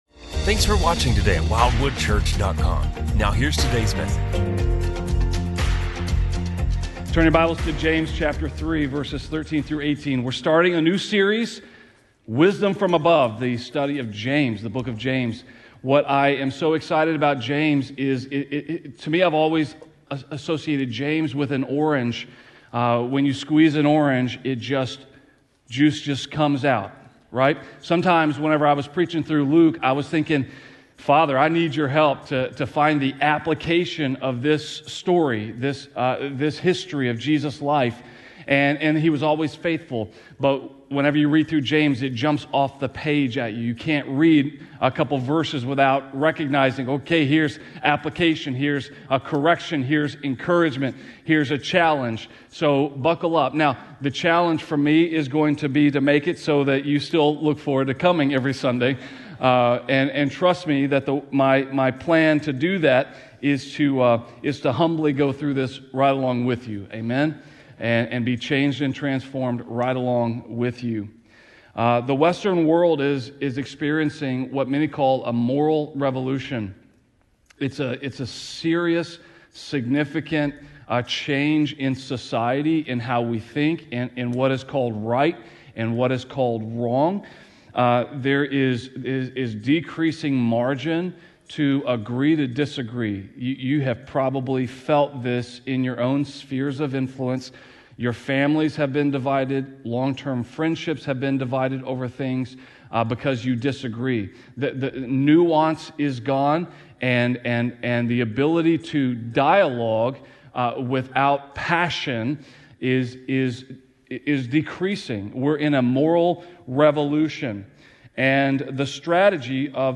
A message from the series "Wisdom From Above." We are not in control of our destiny as the world would have us believe. Rather God is sovereign over all aspects of our lives.